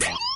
Forza Perc.wav